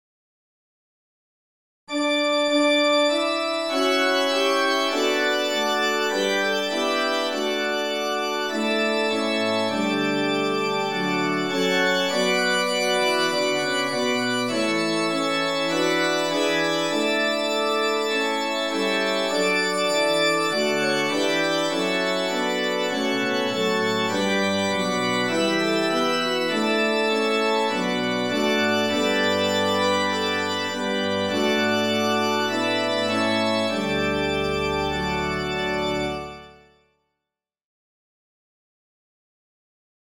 This reharmonization of the hymn tune